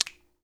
Click7.wav